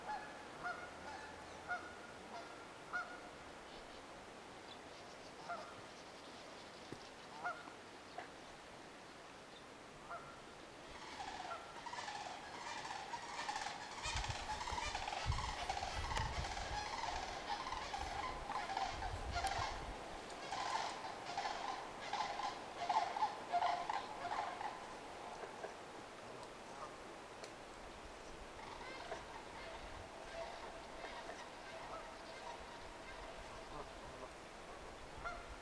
Calls of the Tree Swallows, Sandhill Cranes, and Geese
First and last you hear the geese (of course); after about 4 seconds you hear tree swallows sounding like hundreds of bees buzzing and mice squeaking; then you hear the sandhill cranes: